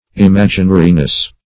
Search Result for " imaginariness" : The Collaborative International Dictionary of English v.0.48: Imaginariness \Im*ag"i*na*ri*ness\, n. The state or quality of being imaginary; unreality.